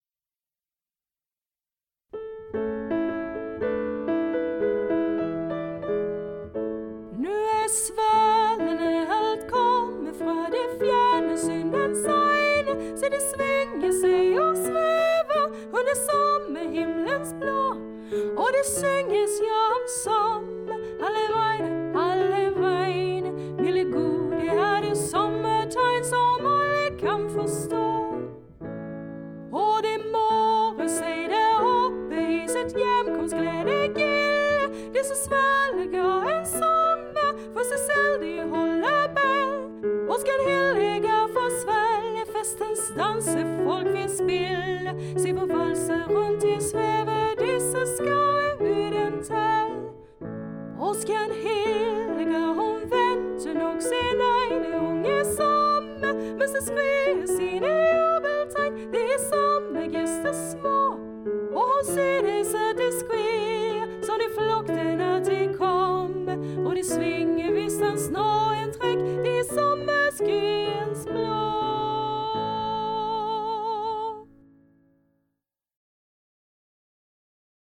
Musik och arrangemang och piano